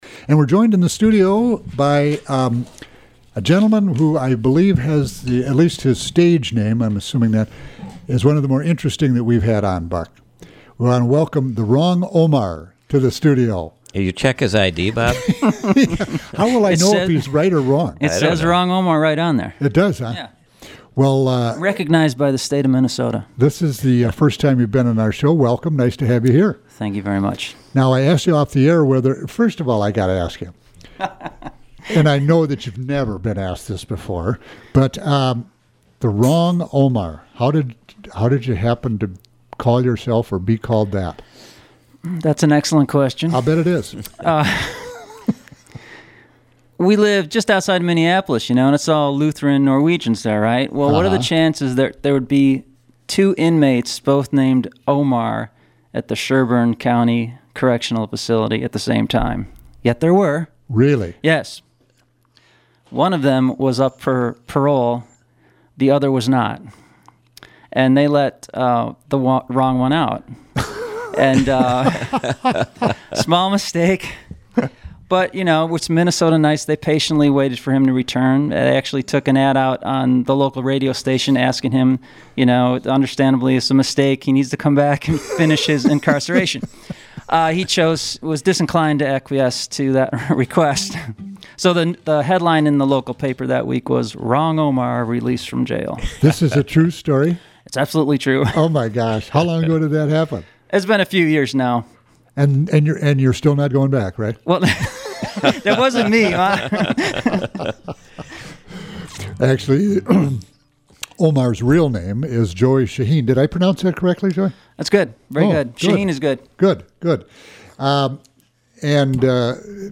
His songs are original, quirky, and fun.
Live Music Archive